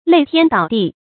擂天倒地 注音： ㄌㄟˊ ㄊㄧㄢ ㄉㄠˇ ㄉㄧˋ 讀音讀法： 意思解釋： 呼天搶地，形容哭喊 出處典故： 明 凌濛初《二刻拍案驚奇》第15卷：「江老夫妻女兒殺豬也似的叫喊， 擂天倒地 價哭。」